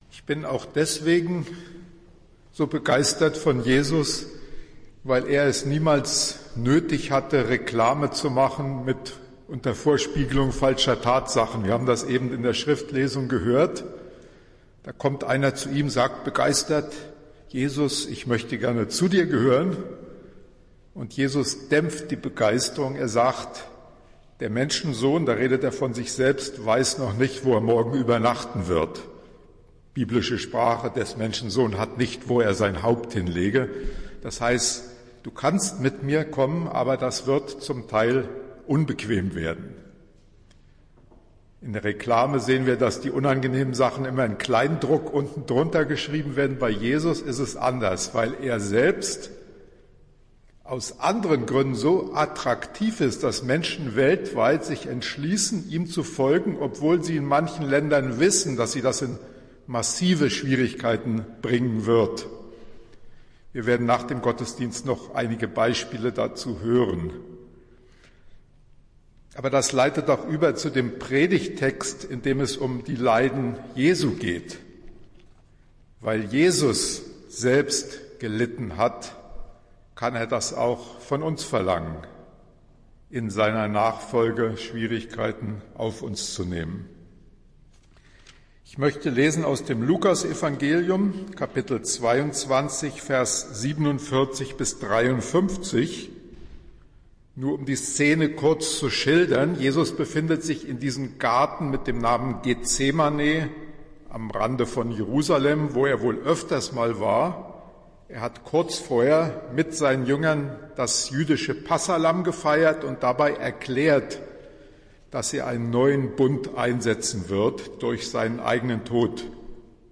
Predigt des Gottesdienstes aus der Zionskirche vom Sonntag, 12. März 2023